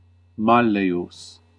Ääntäminen
IPA : /ˈmæ.lɪt/